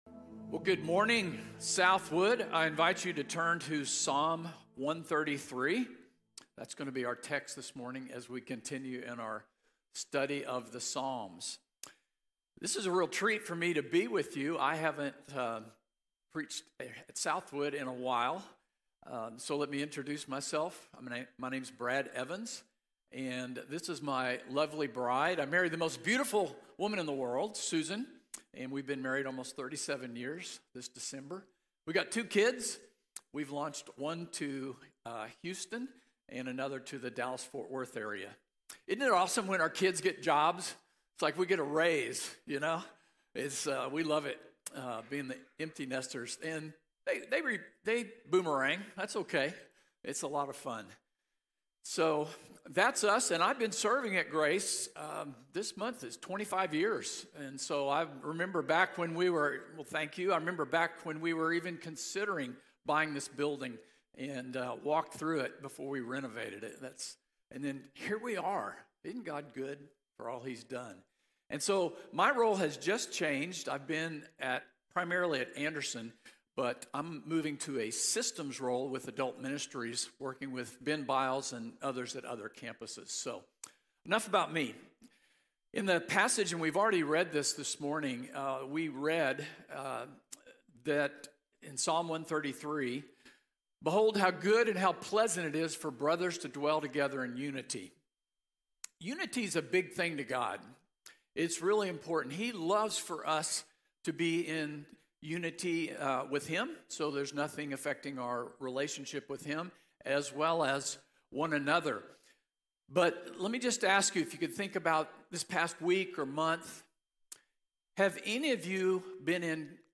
Unity in the Community | Sermon | Grace Bible Church